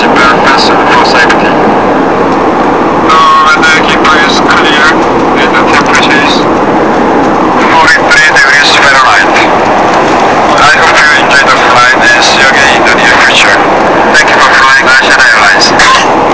pilot of Asiana airplane, Soonchun to Seoul 001.wav